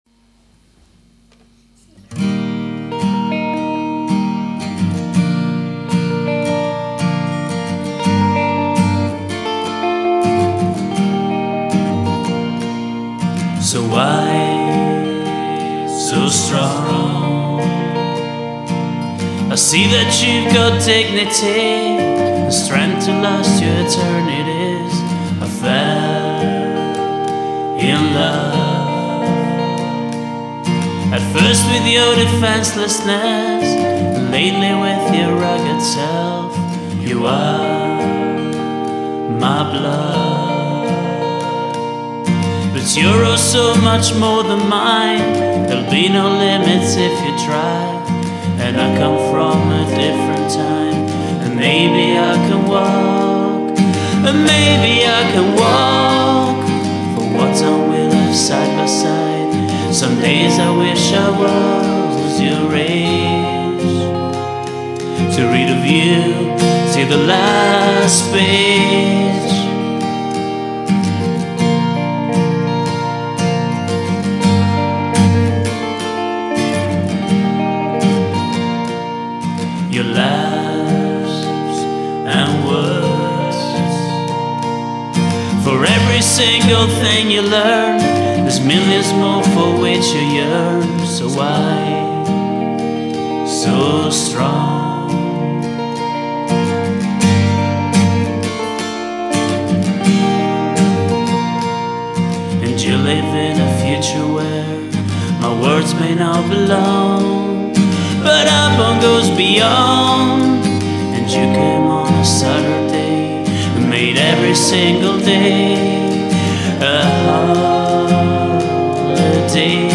vocals, guitars
backing vocals